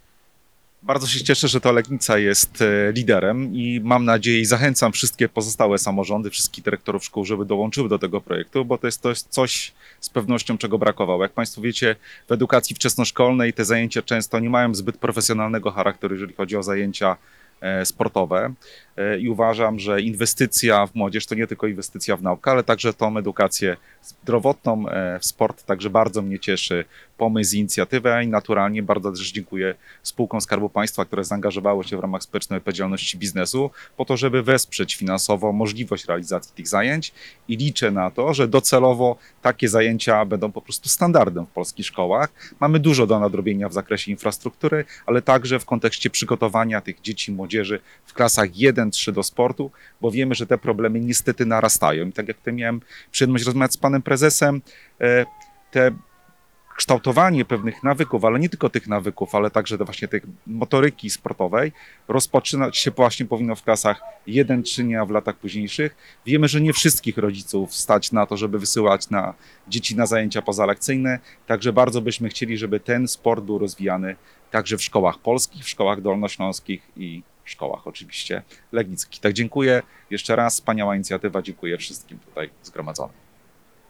Dla władz miasta to oczywiście powód do dumy – co podkreśla prezydent Maciej Kupaj: